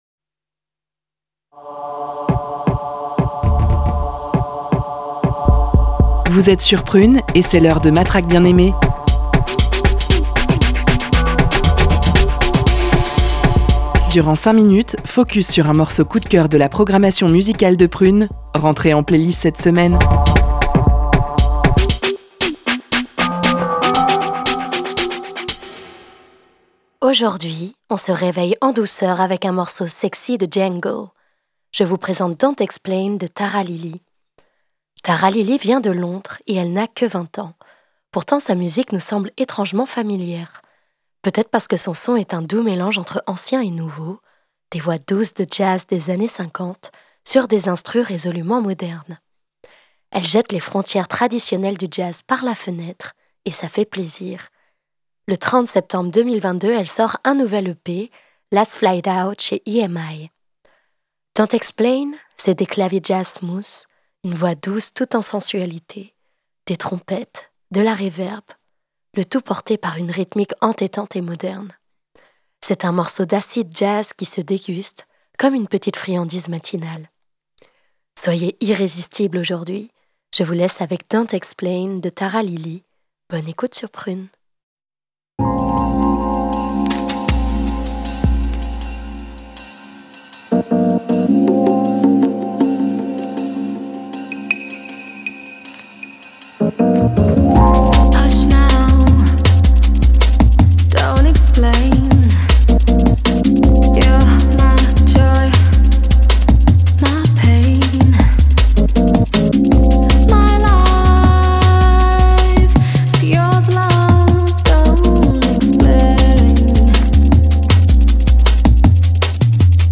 De l'acid jazz